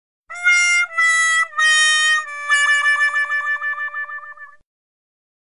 Звуки правильного и неправильного ответа
Звук неправильного ответа с ошибкой для презентации